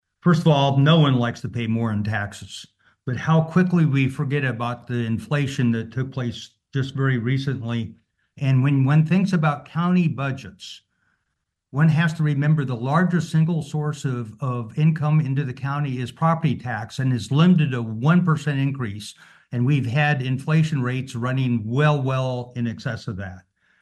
Commissioner Randy Johnson before the vote: